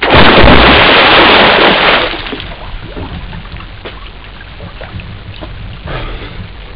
inwater.wav